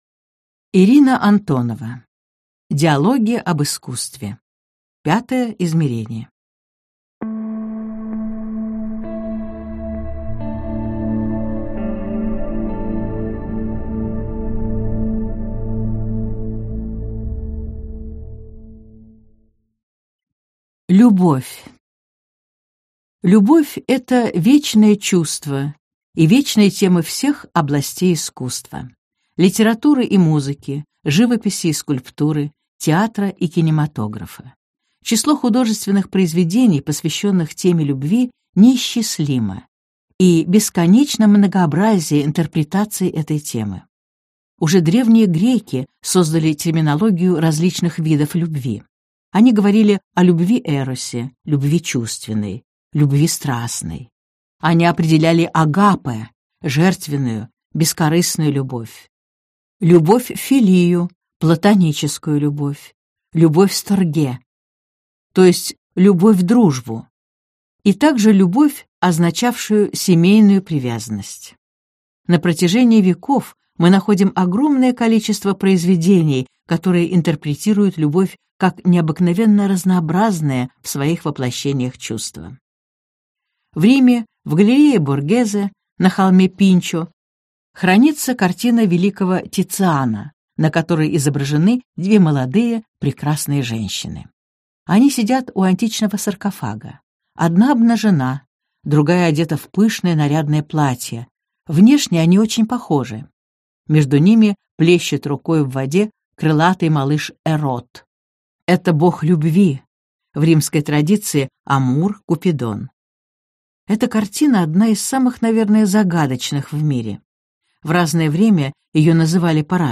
Аудиокнига Диалоги об искусстве. Пятое измерение | Библиотека аудиокниг